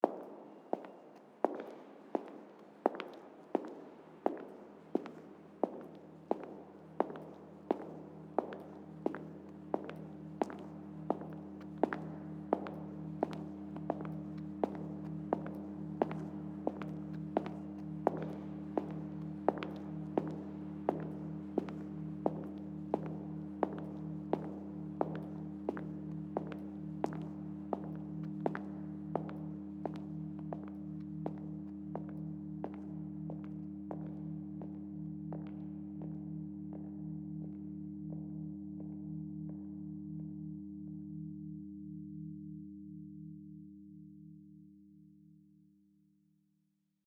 Compositional excerpts were created using a mixture of recognizable, real-world field recordings, processed recordings and synthetic pitched materials. Several excerpts exhibit phonographic approaches, while others involve sound-image transformations (i.e. sonic transmutation between two recognizable sounds) or interplay between synthetic pitched materials and concrète materials. The excerpts contained some repeating sounds — for instance, the sound of footsteps — while other sounds appeared only once.